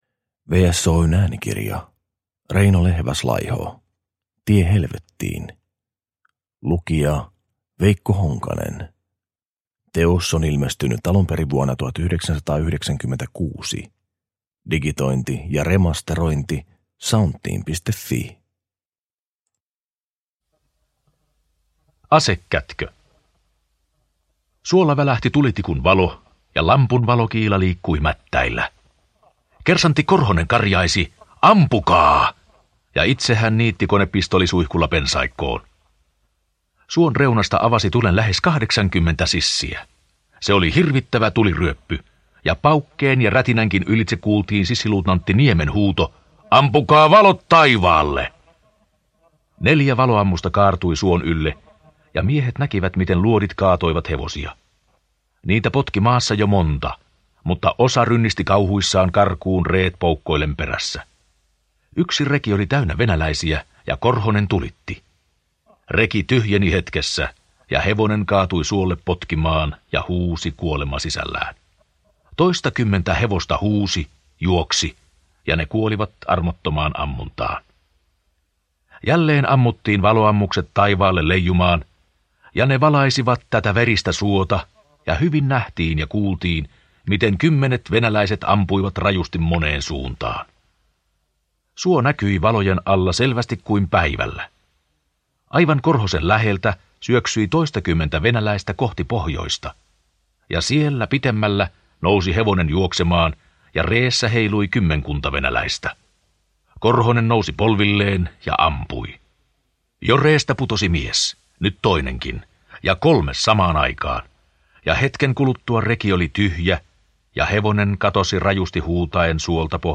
Tie helvettiin – Ljudbok – Laddas ner